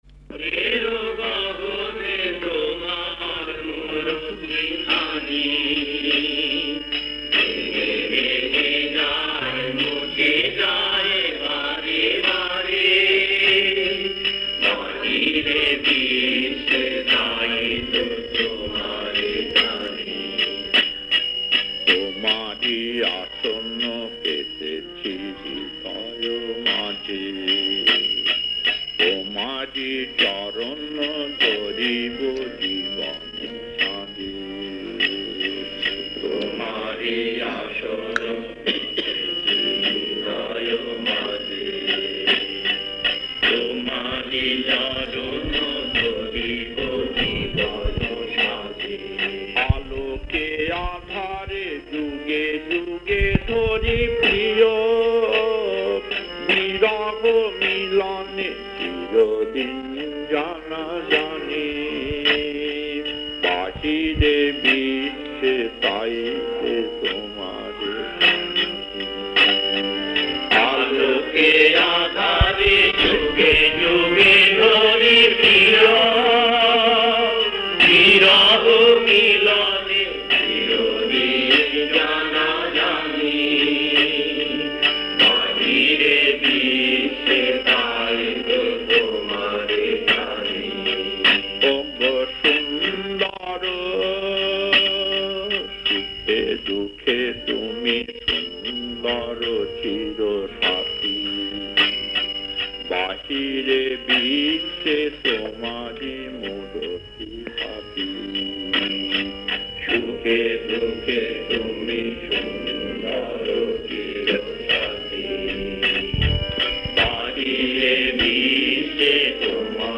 Kirtan E12-1 1: Ogo Sundor Moneri Gohoney 2: Namo Narayan 3: Swapaney Tomare Peyechi 4: Jibone Jekhane Je Ananda 5: Namo Narayan 6: Ogo Debota Tomar 7: Jai Krishna Gobindo 8: Bhojo Radhekrishna Gobindam